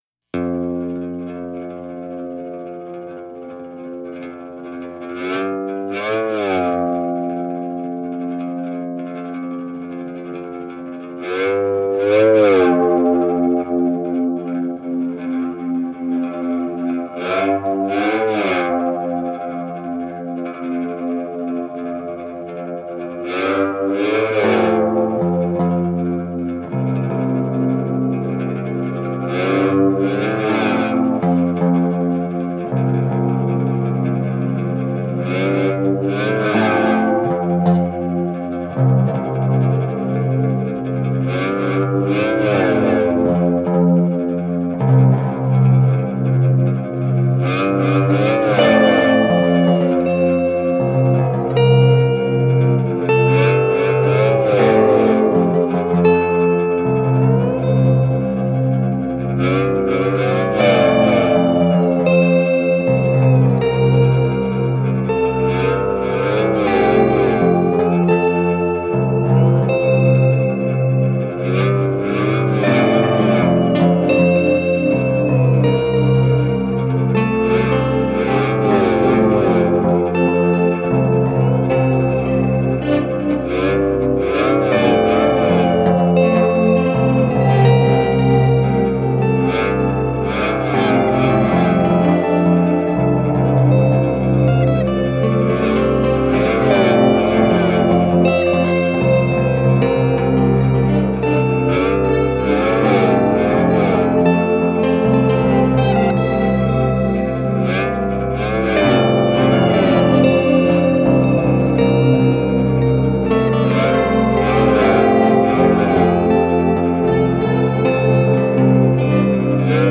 lush, textural guitar soundscapes